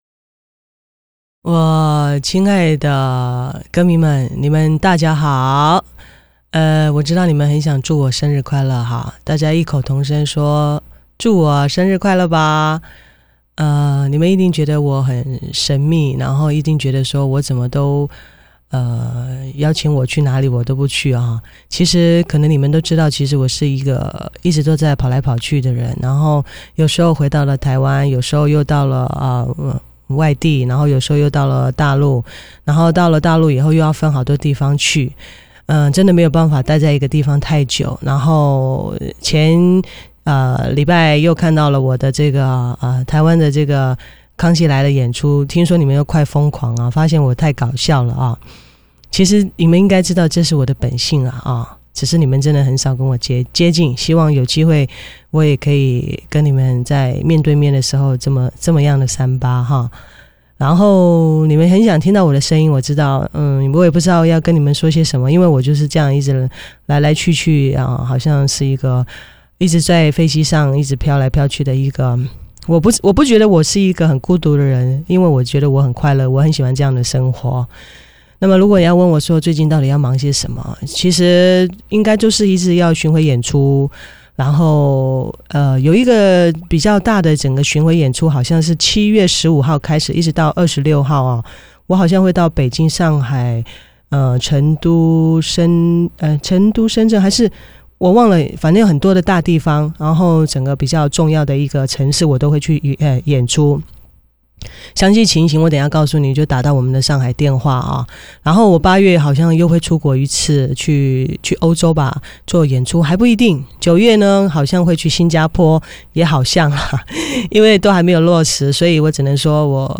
带着她在生日前夕特地录制以回馈给所有歌迷的一段话走来了！
不过声音略显疲惫，潘美不要太累了，要注意身体啊！